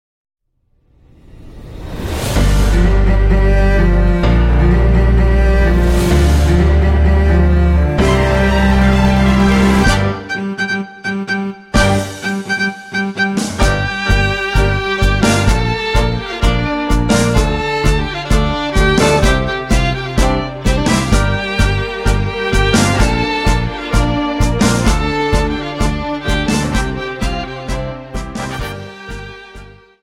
Dance: Tango Song